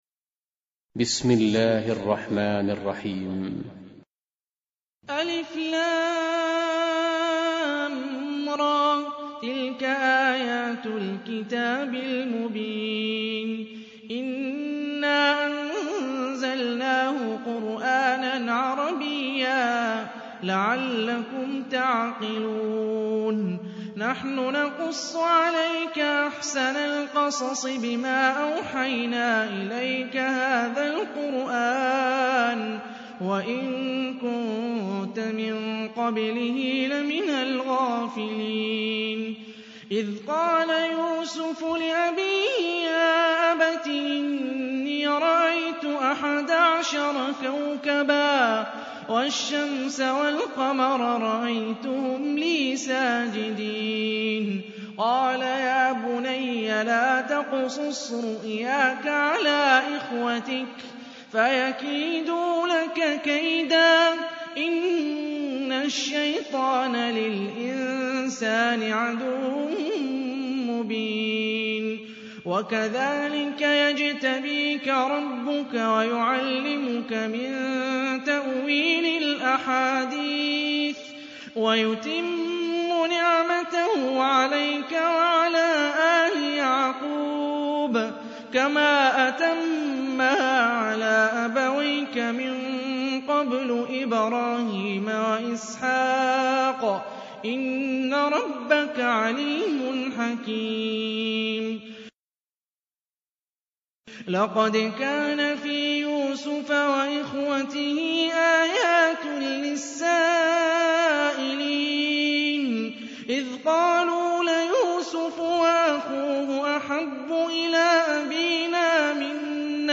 Қуръони карим тиловати, Қорилар.